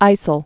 (īsəl)